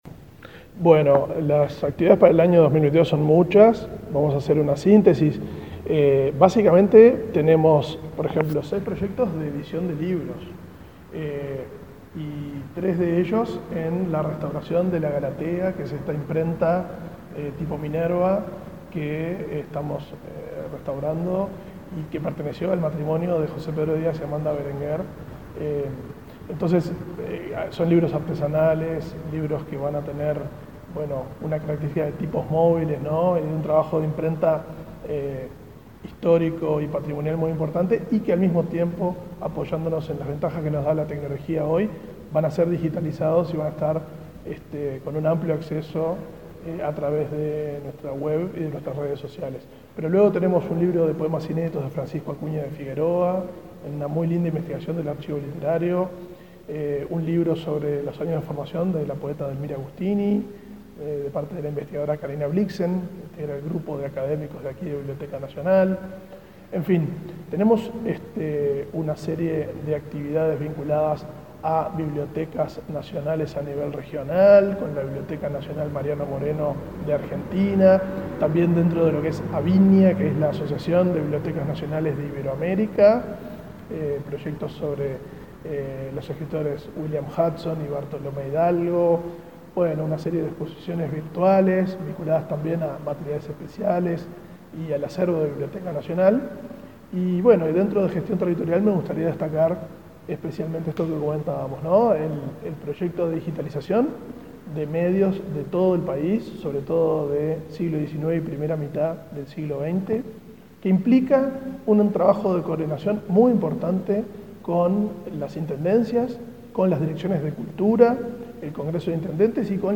Entrevista al director de la Biblioteca Nacional, Valentín Trujillo